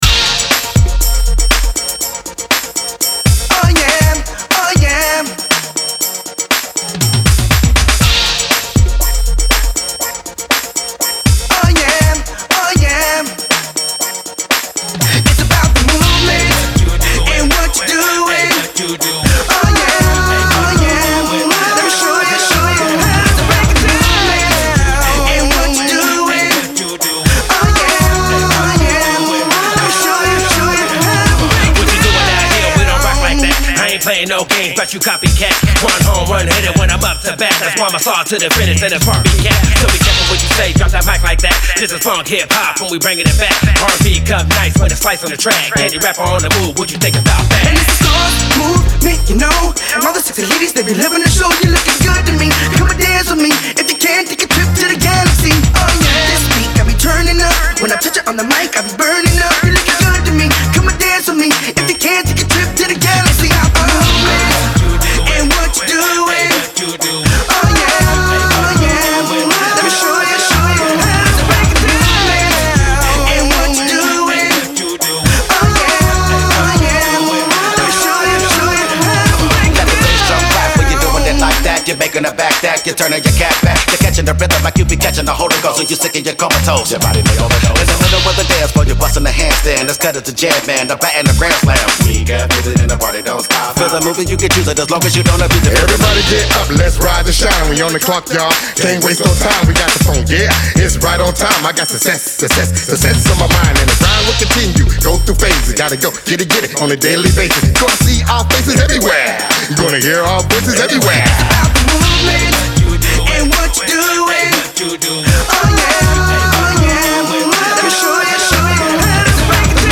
Punk
8 man hip-hop/funk group from Portland, Oregon